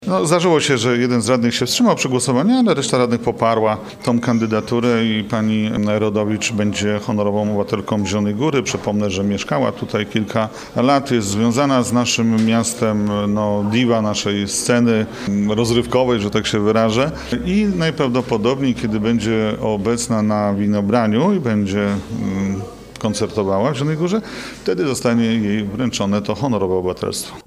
Jednym z najważniejszych punktów dzisiejszej sesji rady miasta było przegłosowanie uchwały w sprawie przyznania piosenkarce Maryli Rodowicz Honorowego Obywatelstwa Miasta Zielona Góra.
Jacek Budziński, przewodniczący klubu Prawa i Sprawiedliwości przypomniał, że Maryla Rodowicz jest rodowitą zielonogórzanką, a swoją postawą i twórczością zasługuje na to ważne wyróżnienie: